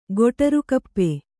♪ goṭaru kappe